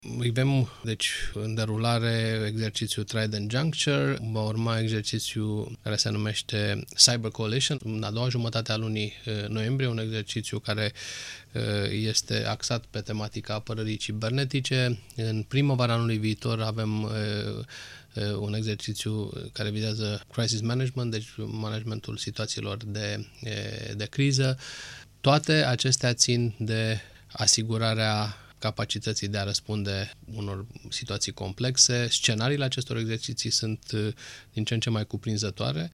Învitat la Interviurile Europa FM, Sorin Ducaru a explicat că NATO nu poate interveni în conflictul care a ucis până acum peste 250 de mii de oameni până ce Consiliul de Securitate al ONU nu va adopta o rezoluție în acest sens.